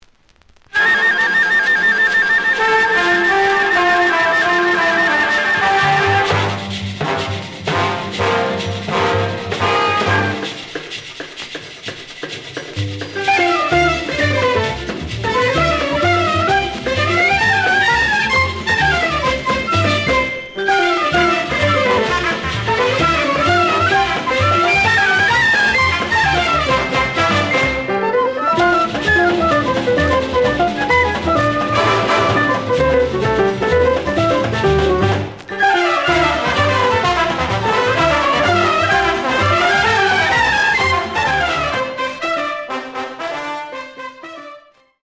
The audio sample is recorded from the actual item.
●Genre: Jazz Other
Some noise on first half of A side due to cloudy.)